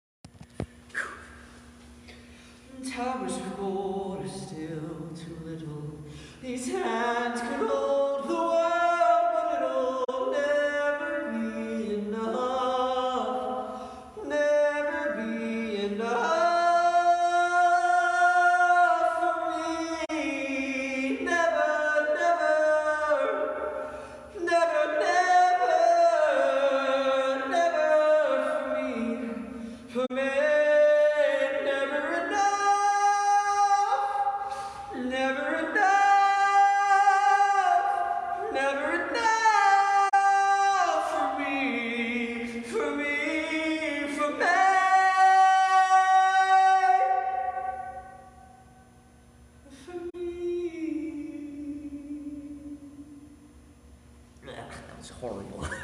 singing like shit at 1:30am in a parking garage stairwell.